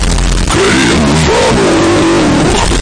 Звук где громко произносят слово Кринжанул